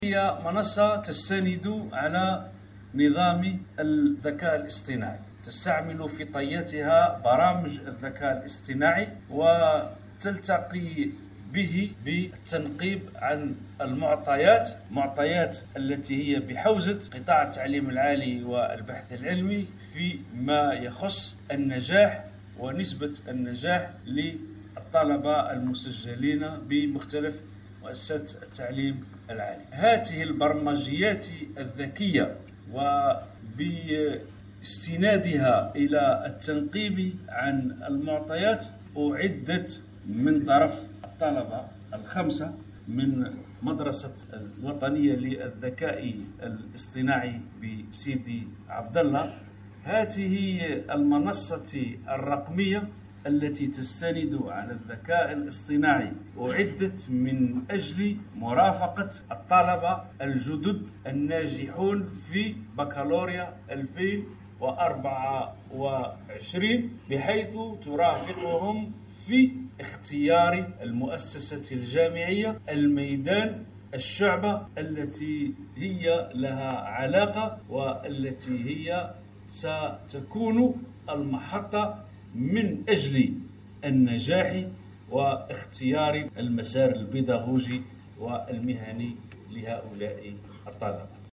30 أبريل 2024 برامج إذاعية 0